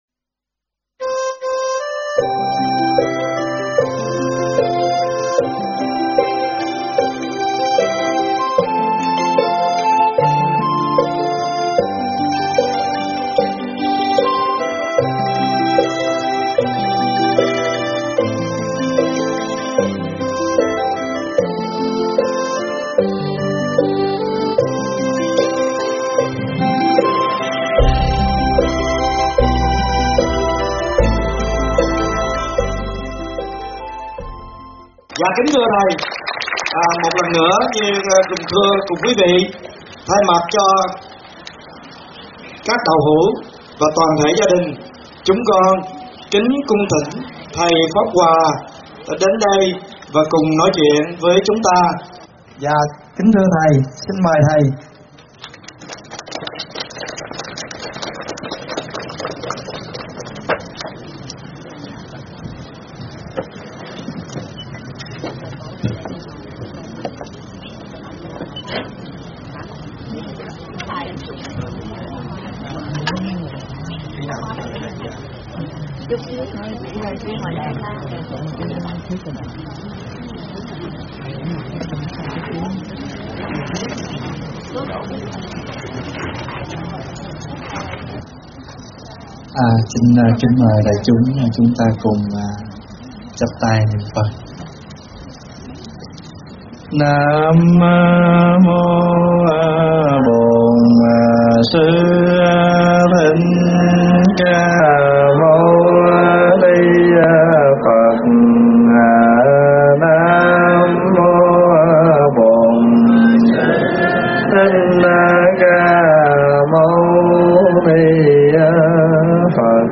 Thuyết pháp Khi Rồng Khi Hổ - Thầy Thích Pháp Hòa